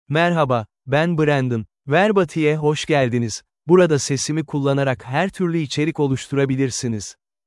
BrandonMale Turkish AI voice
Brandon is a male AI voice for Turkish (Turkey).
Voice sample
Listen to Brandon's male Turkish voice.
Male